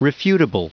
Prononciation du mot refutable en anglais (fichier audio)